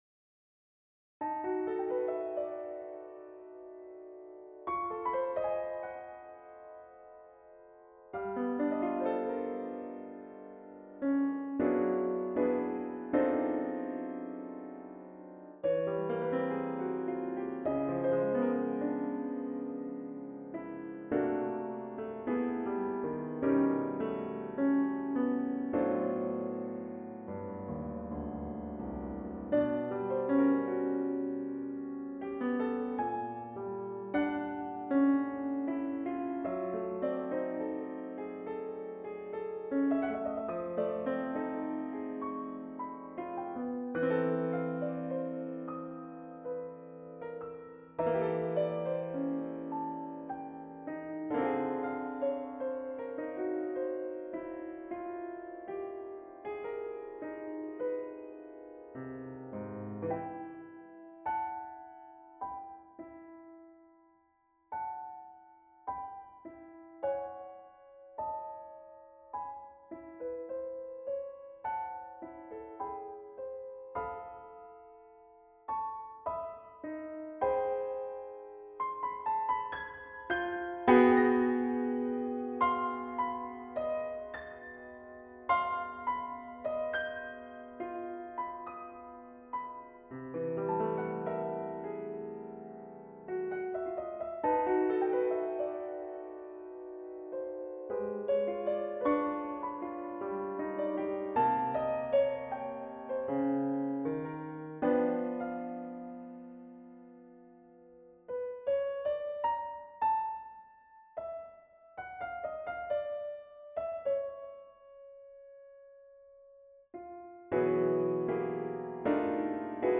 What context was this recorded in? Unperformed work, so just computer realisations (my apologies).